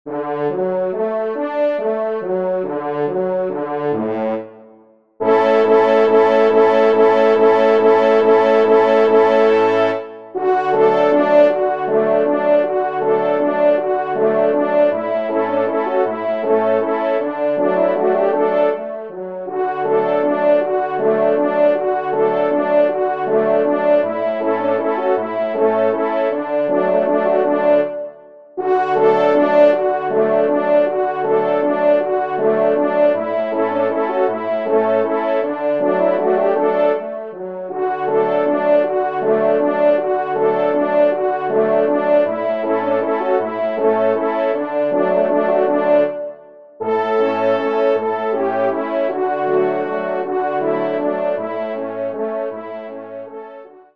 Valse
Genre :  Divertissement pour Trompes ou Cors en Ré (Valse)
ENSEMBLE